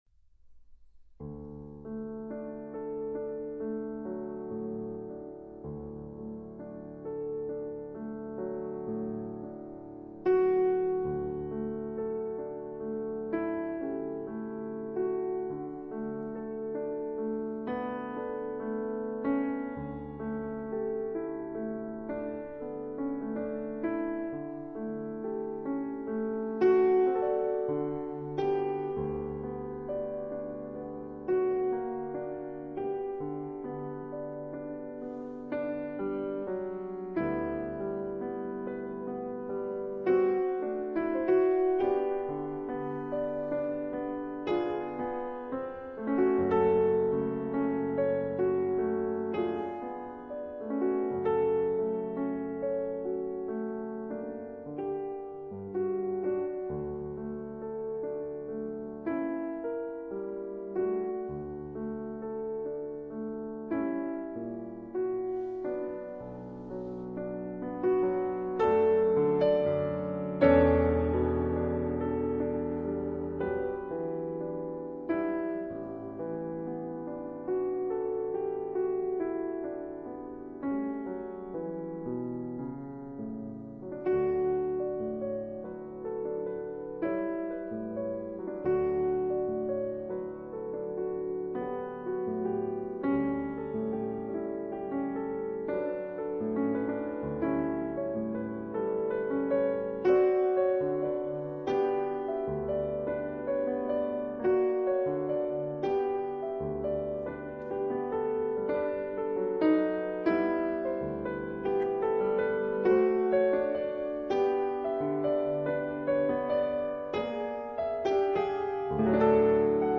Piano Pieces